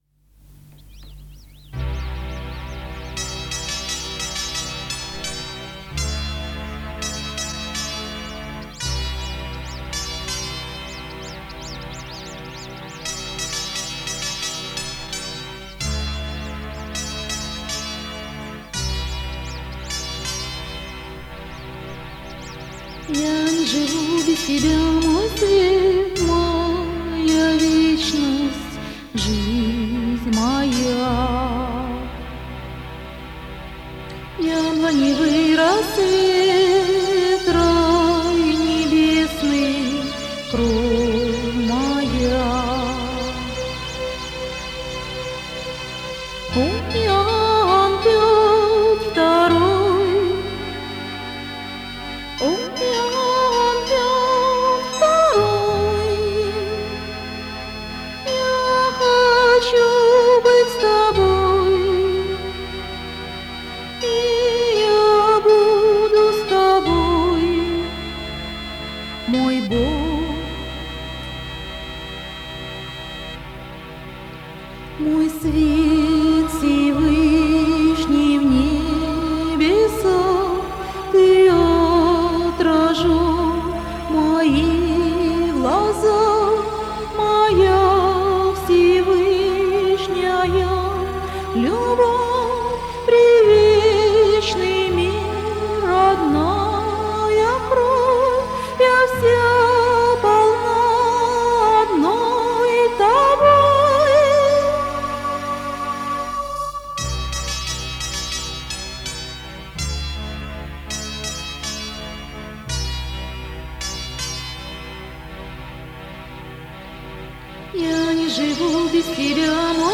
Мистическая музыка Духовная музыка